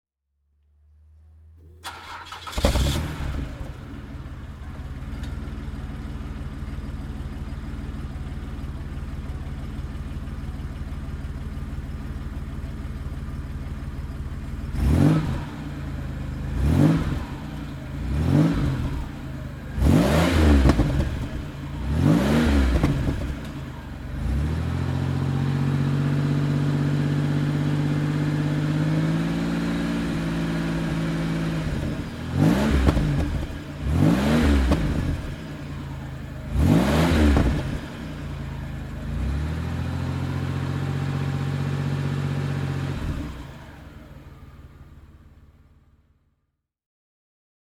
Buick Reatta Convertible (1991) - Starten und Leerlauf
Buick_Reatta_1991.mp3